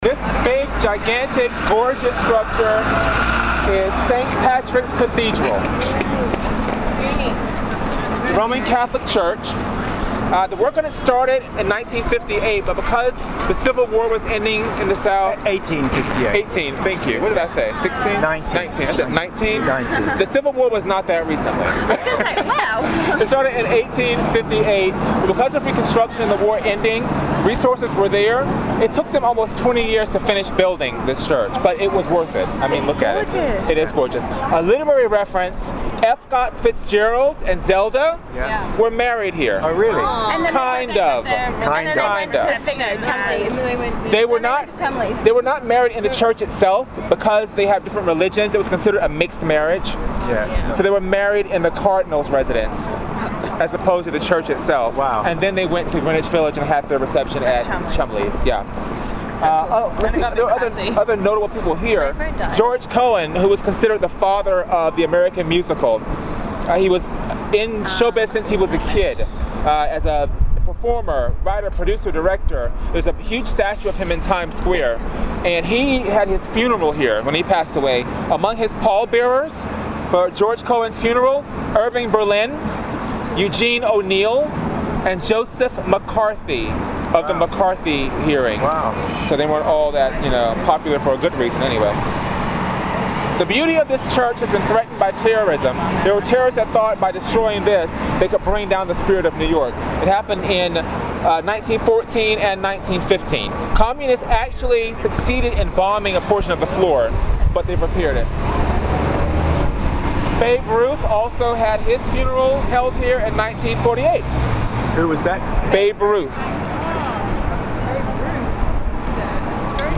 Or was it actually in the Cathedral where they got married? Here is our guide: